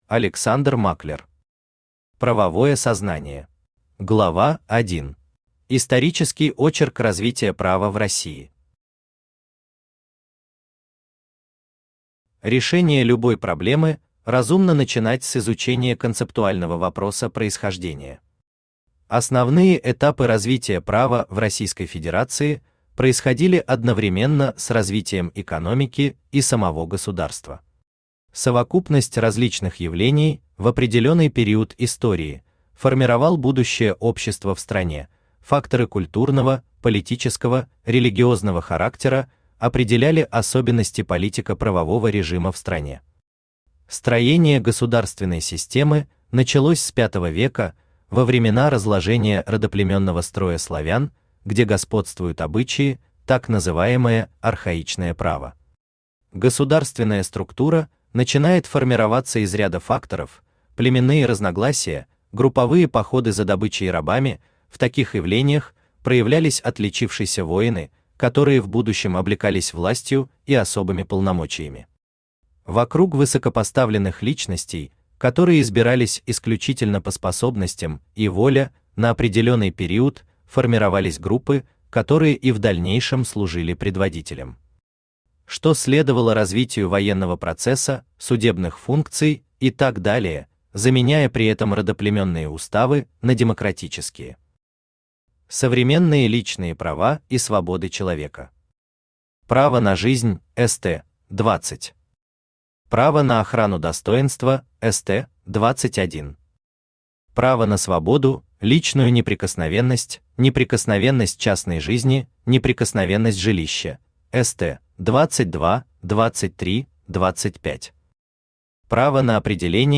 Aудиокнига Правовое сознание Автор Александр Германович Маклер Читает аудиокнигу Авточтец ЛитРес.